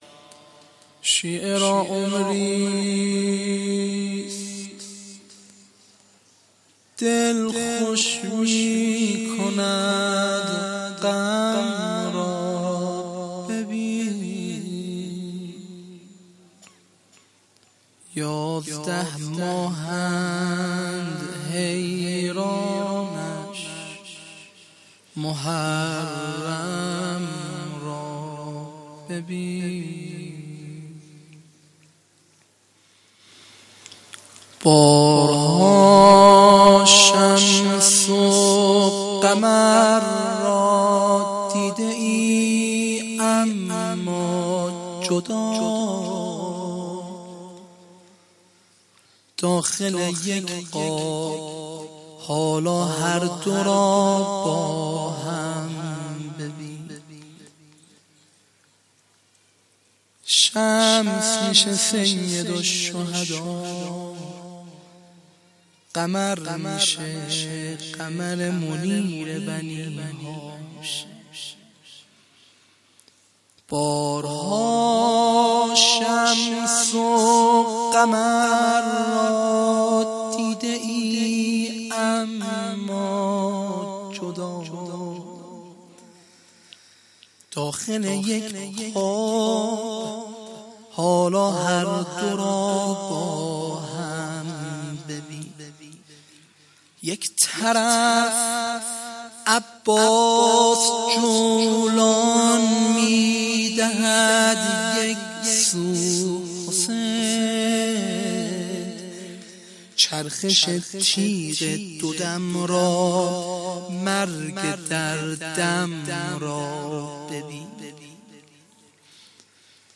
خیمه گاه - هیأت جوانان فاطمیون همدان - شب دوم ( روضه )